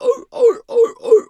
pgs/Assets/Audio/Animal_Impersonations/seal_walrus_2_bark_04.wav at master
seal_walrus_2_bark_04.wav